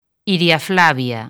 Transcripción fonética
ˈiɾja̝ˈflaβja̝